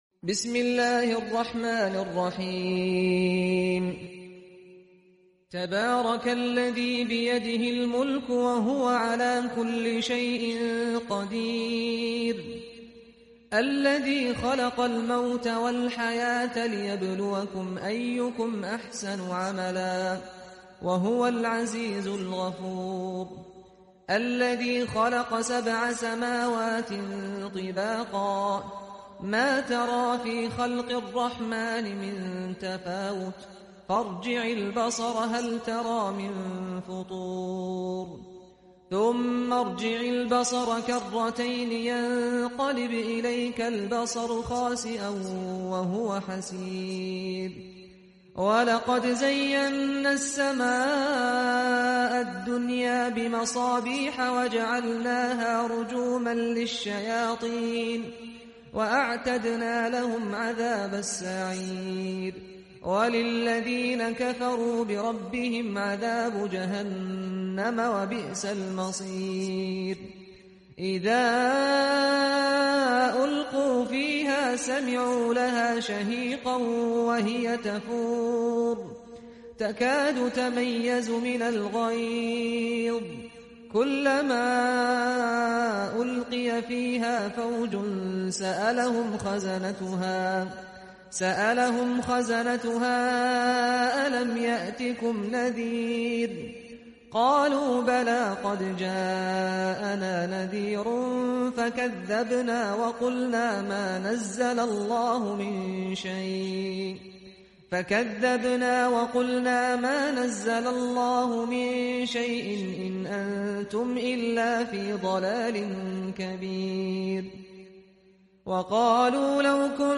Qur'an surah al mulk Qur'an recitation